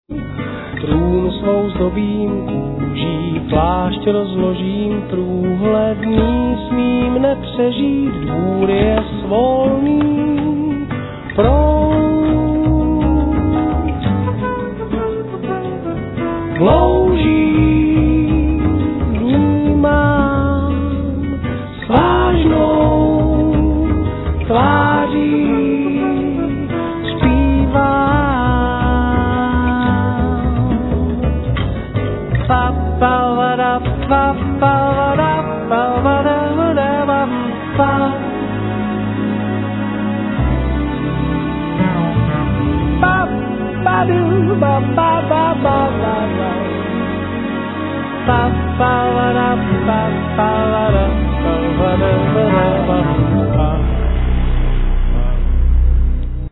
Strings:
Vocals
Guitar synthes,Ac.guitar samples
Fender piano,Djembes Egg,Synthes
Double. bass
Drums